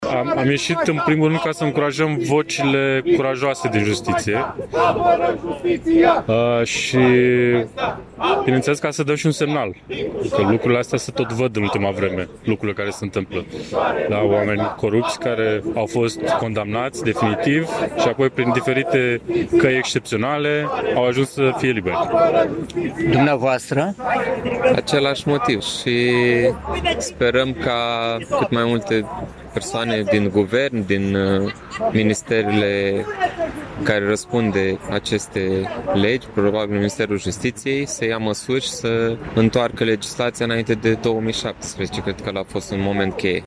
La Iași, peste 100 de persoane s-au strâns în fața  tribunalului, în urma investigaţiei Recorder.
12-dec-rdj-20-vox-pop-protest-justitie.mp3